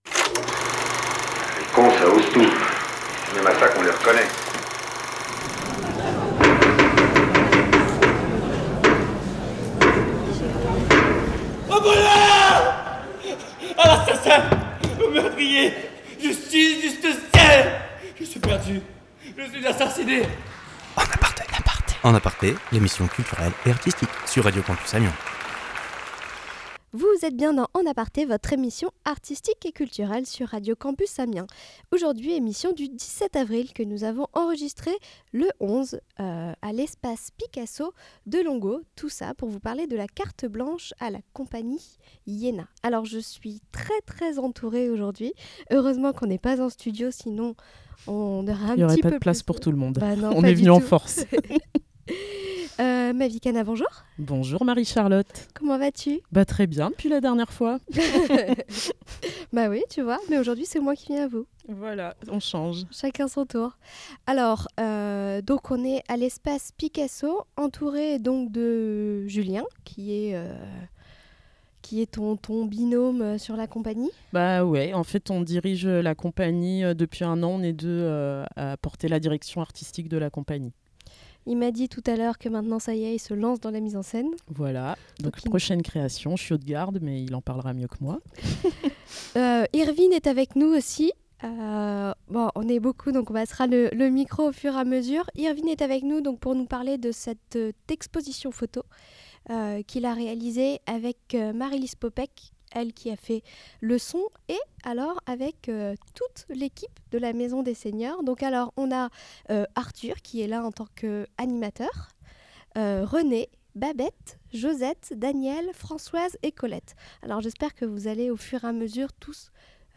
Pour la troisième émission avec eux, je me suis rendue à l’Espace Picasso de Longueau pour leur Carte Blanche à la Compagnie Yaena du 26 au 28 avril 2019.